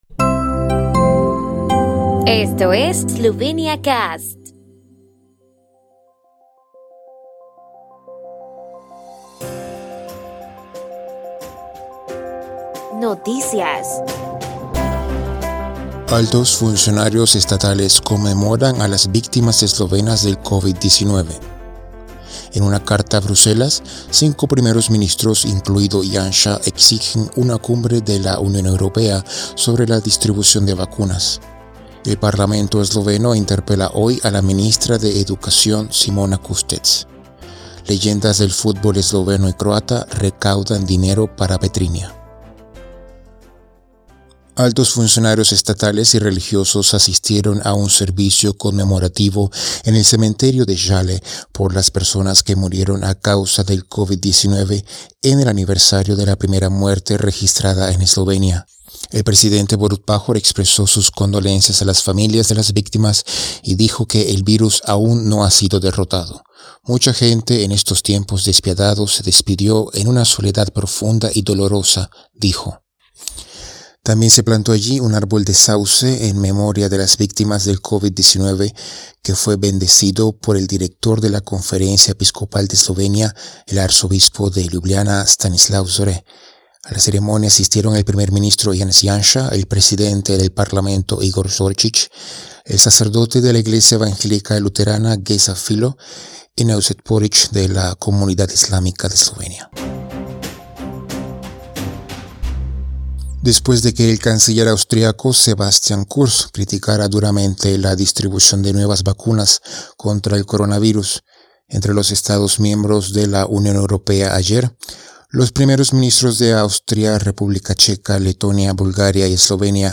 En directo de lunes a viernes a las 11:00 UTC, 12:00 CET, 06:00 CO, 07:00 VE, 08:00 AR, CL.